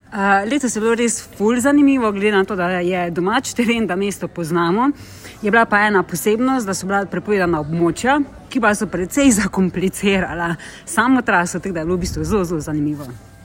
Izjave udeleženk: